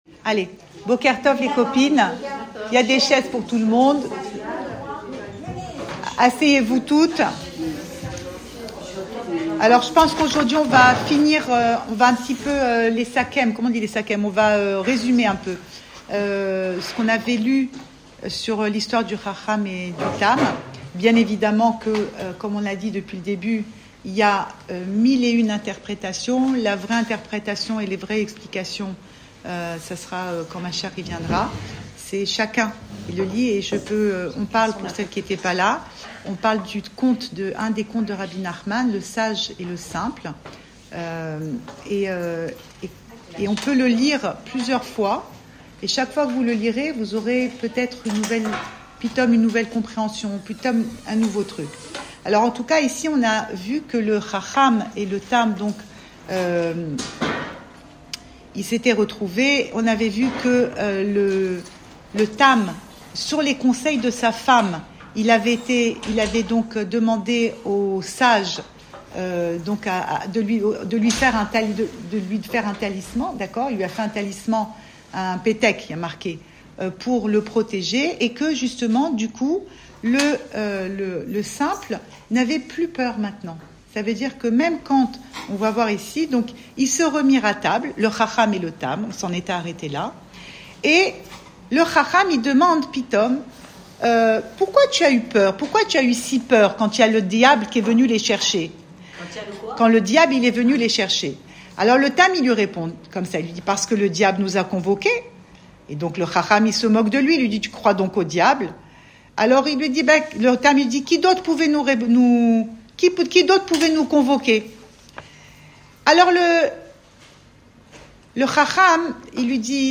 Cours audio
Enregistré à Tel Aviv